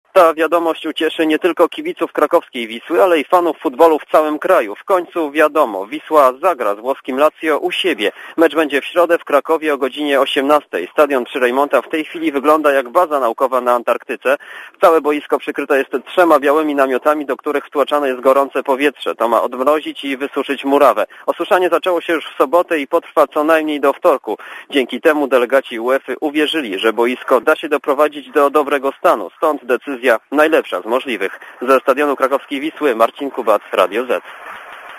Relacja reportera Radia Zet z Krakowa (293Kb)